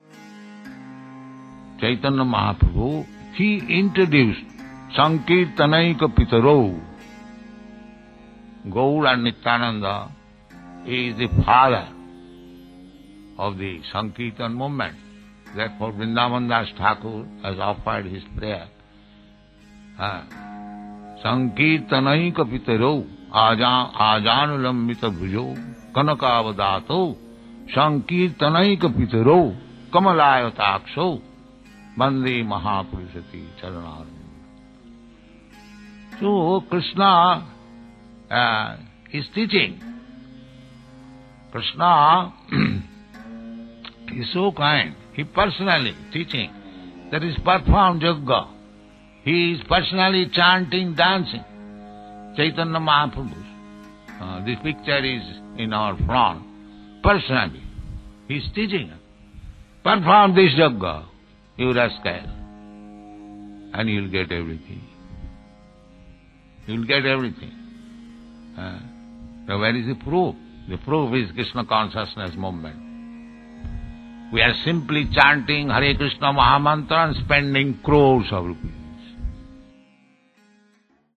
(740928 - Lecture SB 01.08.18 - Mayapur)